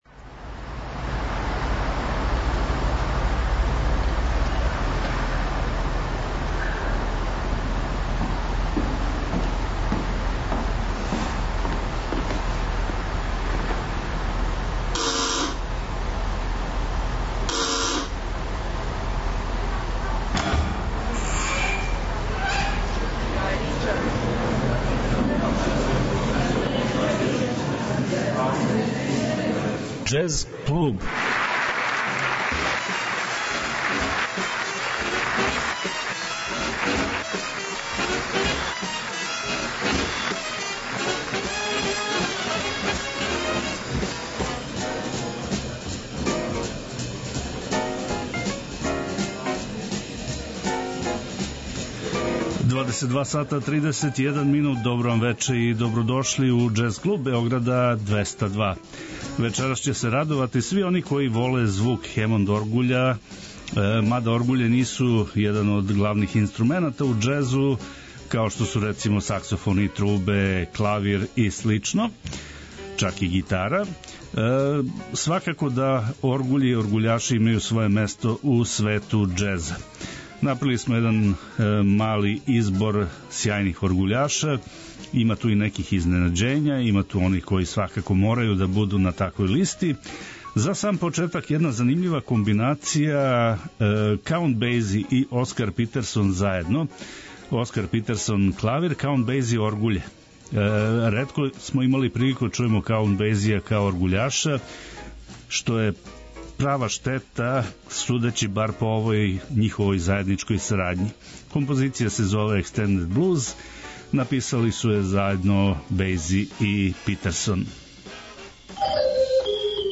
14.15 MB Џез клуб Autor